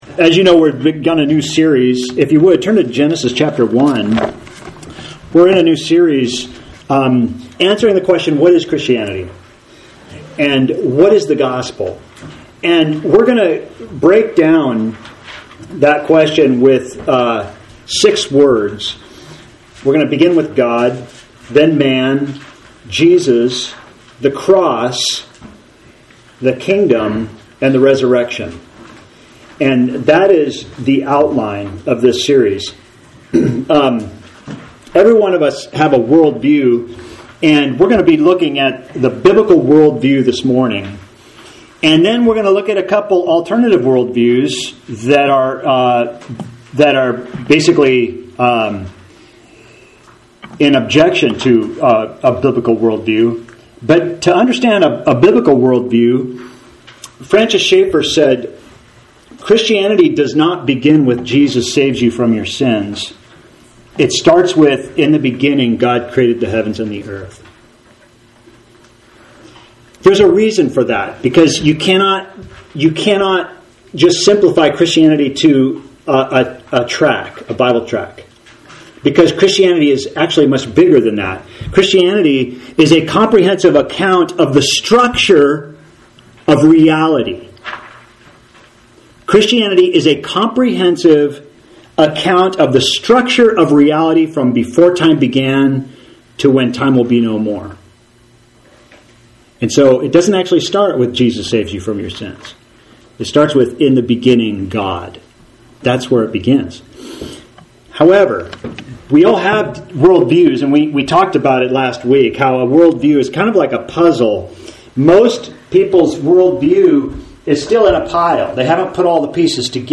AUDIO | TEXT PDF Part 2 of a sermon series: Built on Solid Rock: The Bible Big Picture of Reality.